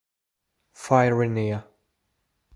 Fyrinia (/ˌfɑːɪrɪˈniə/ (About this sound (